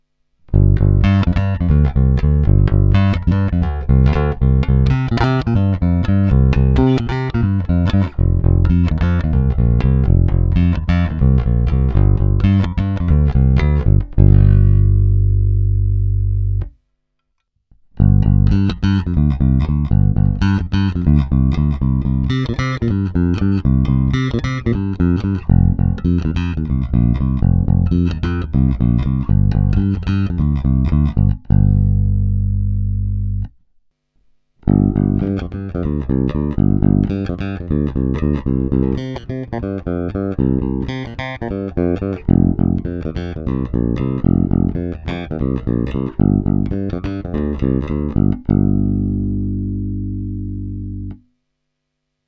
Zvukově je to opravdu typický Jazz Bass se vším všudy.
Není-li uvedeno jinak, následující nahrávky jsou provedeny rovnou do zvukové karty, jen normalizovány, jinak ponechány bez úprav.
Snímač u kobylky